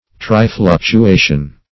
Search Result for " trifluctuation" : The Collaborative International Dictionary of English v.0.48: Trifluctuation \Tri*fluc`tu*a"tion\, n. [Pref. tri- + fluctuation.] A concurrence of three waves.